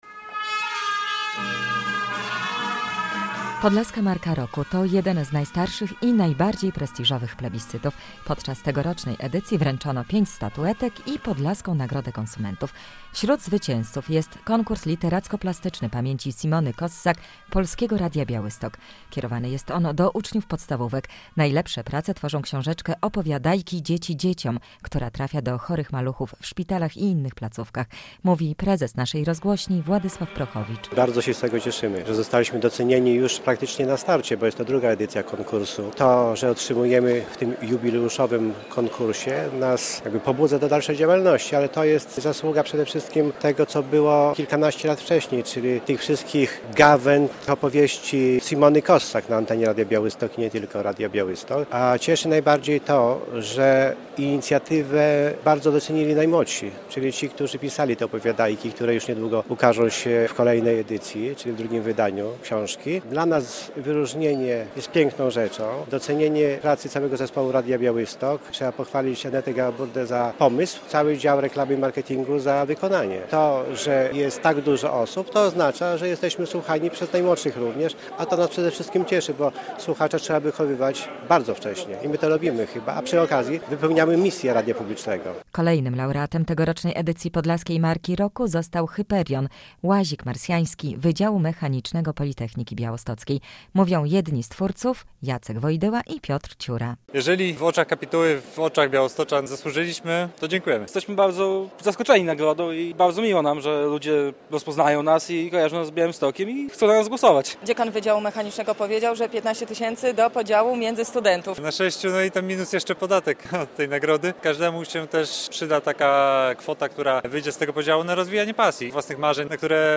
Podlaska Marka Roku 2013 - relacja